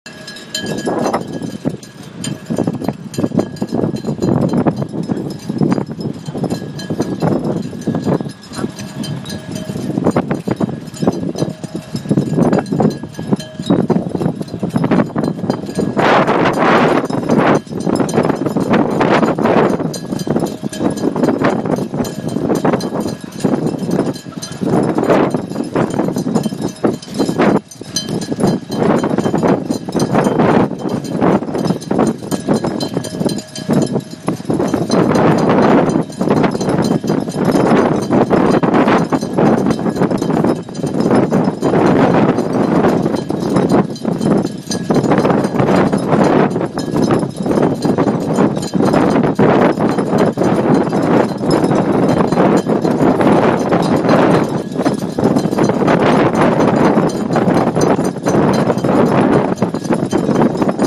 Sounds of Boats on a Windy Beach
Like huge wind chimes, the rigging on these boats rings and jangles in the slightest breeze. Today was blustery, almost wild for the time of year.
beachedboats.mp3